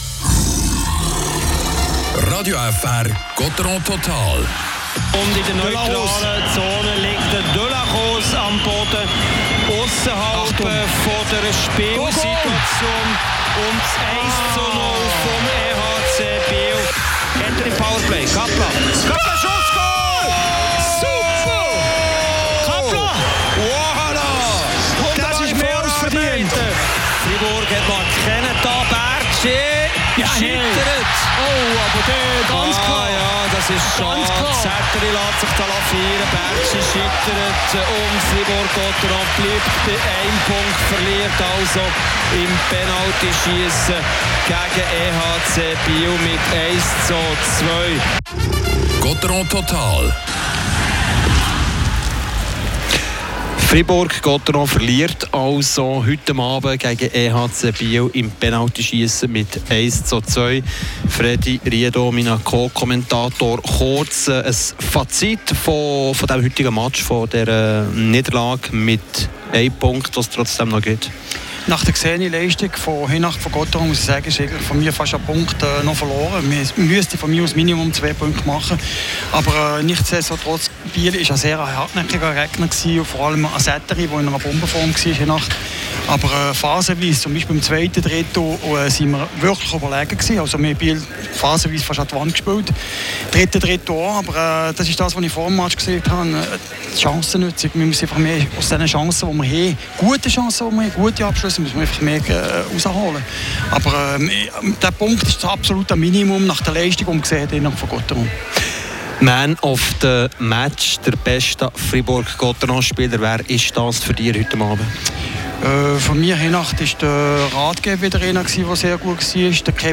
Nach der Verlängerung und dem Penaltyschiessen steht es 2:1 für den EHC Biel. Trotz Niederlage kann Fribourg-Gottéron mit einem Punkt nach Hause kehren. Spielanalyse
Interviews